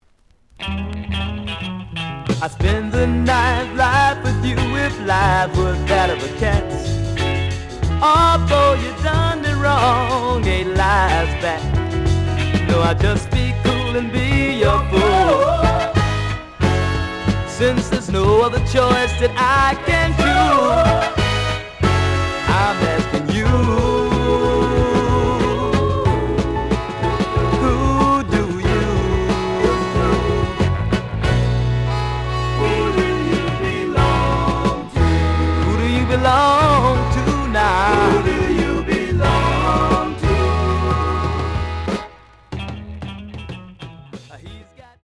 The audio sample is recorded from the actual item.
●Genre: Soul, 70's Soul
Slight sound cracking on both sides.